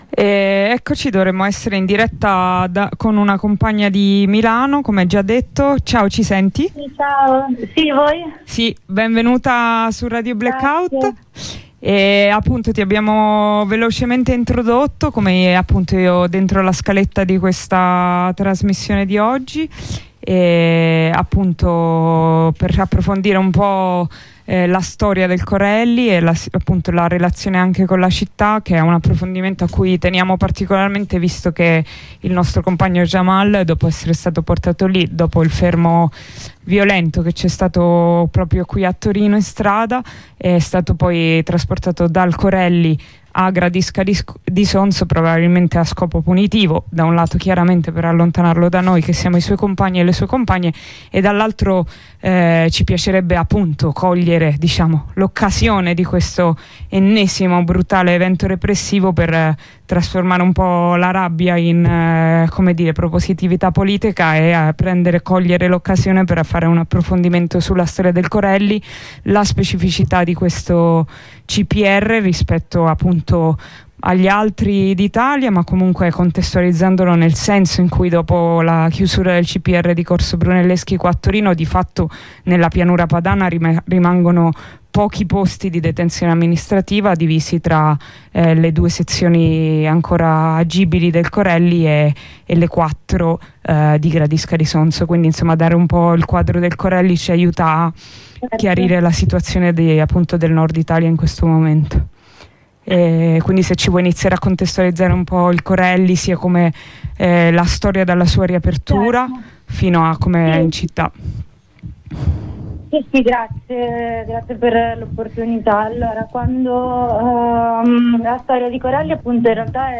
Con questo proposito abbiamo fatto una diretta con una compagna che, oltre a ricostruire la storia del CPR di Milano e la sua posizione in città, si é soffermata con noi ad analizzare il rapporto tra questa prigione e gli equilibri politici cittadini . Non si può che sottolineare infatti quanto il CPR di via Corelli sia la controprova tangibile della tanto ripetuta, quanto vera, costatazione che: i CPR si chiudono da dentro, e con il fuoco.